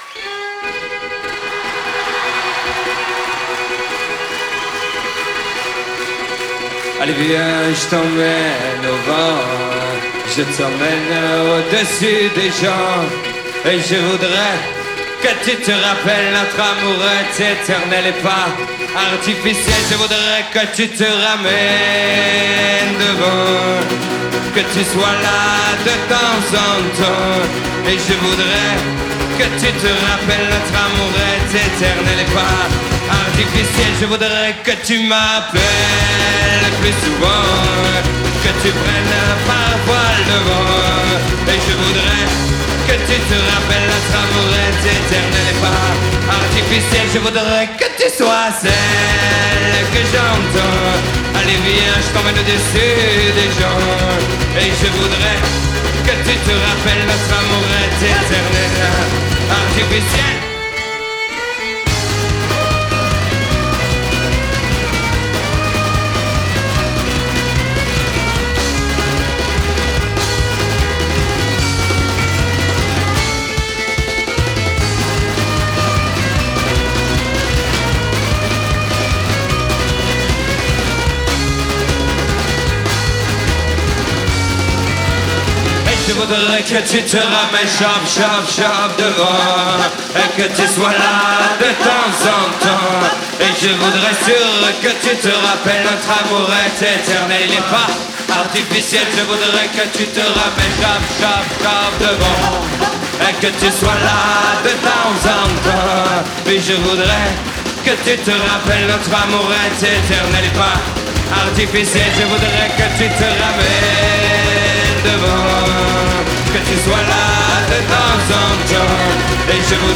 Live à la salle de la Cité, Rennes